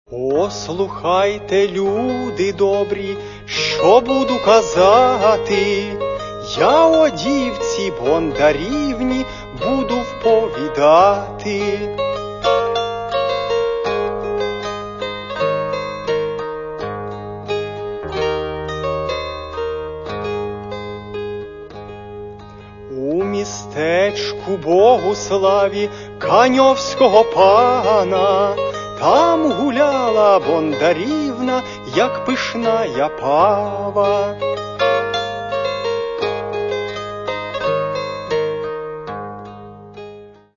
Каталог -> Народна -> Бандура, кобза тощо
Балада